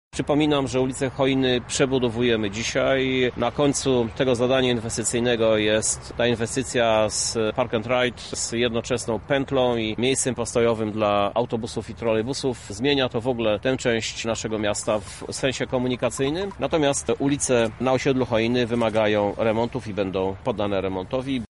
– mówi Prezydent miasta Krzysztof Żuk.